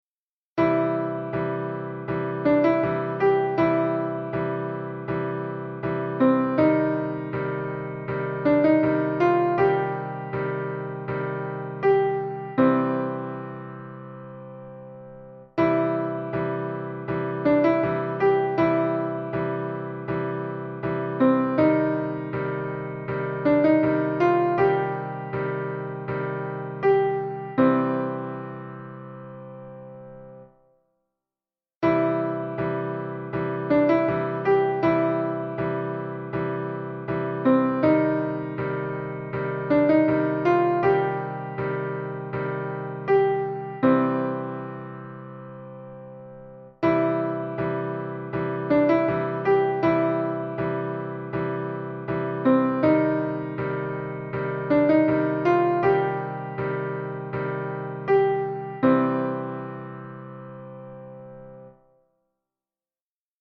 1. Relax & Listen - (entire song)
In this lesson the 2 chords are C major and C minor (Cm).
play-by-ear-lesson-1-c-cm.mp3